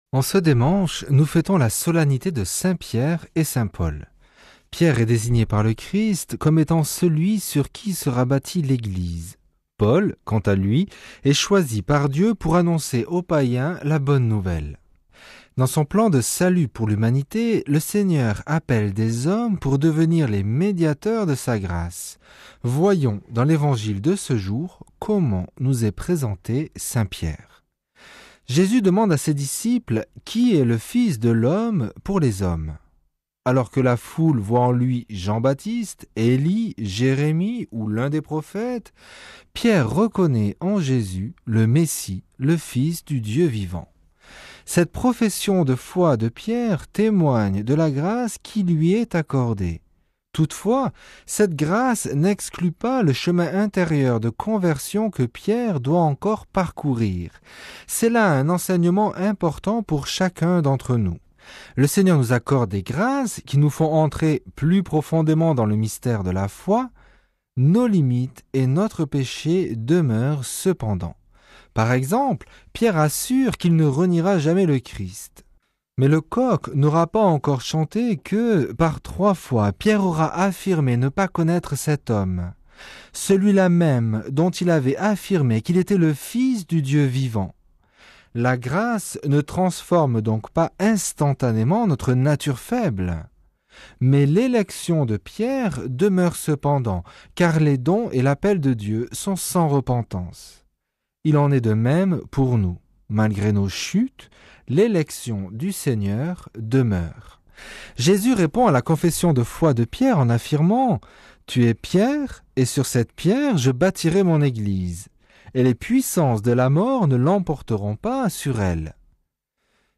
Commentaire de l'Evangile du dimanche 29 juin